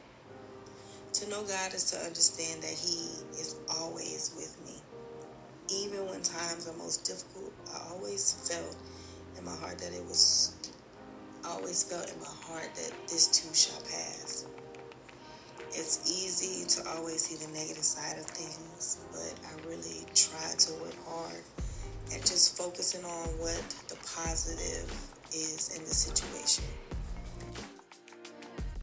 Returning student's Interview